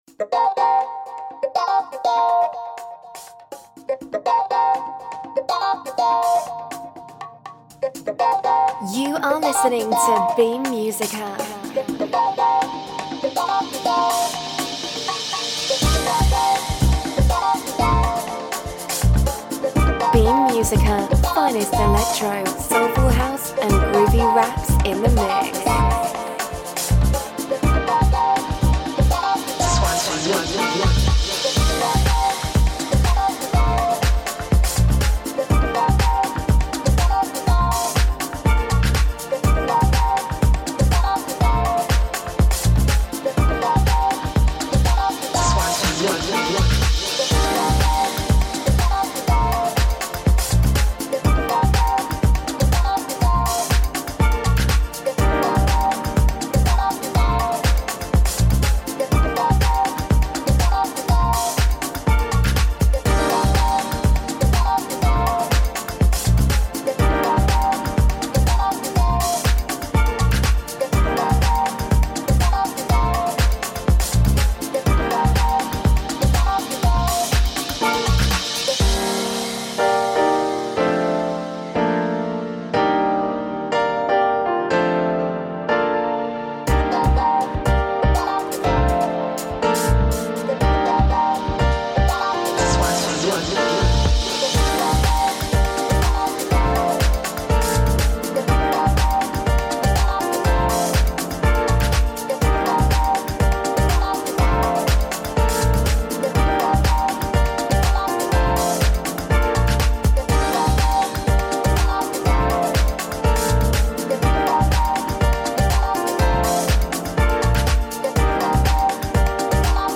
Finest ELECTRO, jazzy HOUSE and soulful RAPS in the MIX